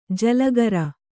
♪ jalagara